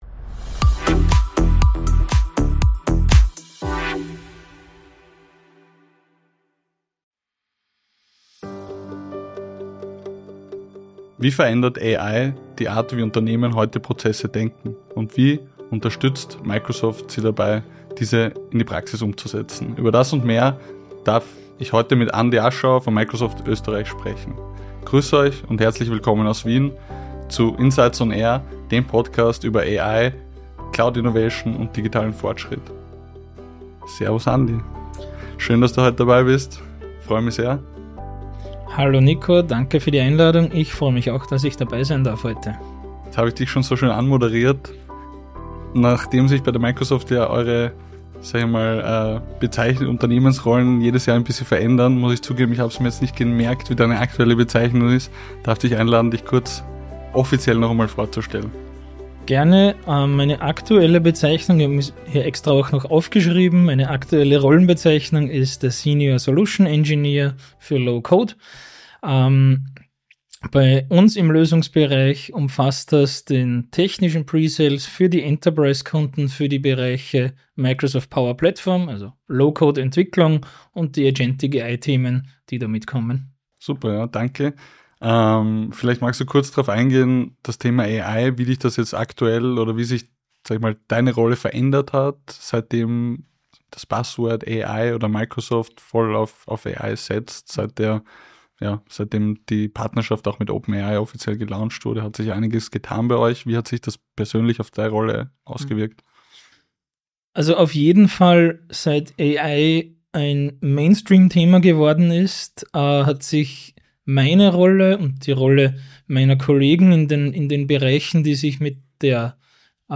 Der Podcast über AI, Cloud Innovation und digitalen Fortschritt – Live aus Wien.